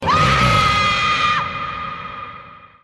Scream